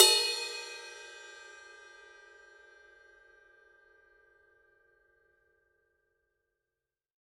Type Ride
20_ride_bell.mp3